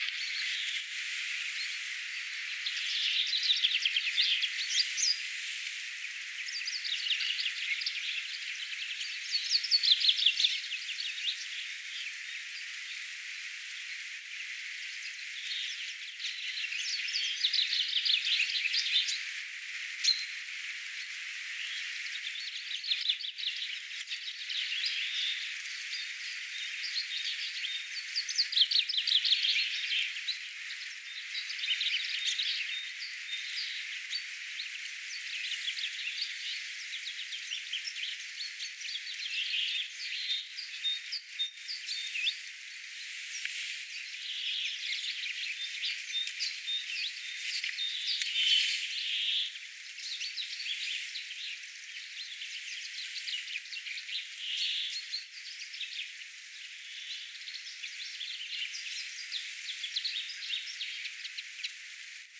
peipot laulavat
Tässä parvessa oli arviolta noin 300 lintua.
peipot_laulavat_satojen_peippojen_parvessa.mp3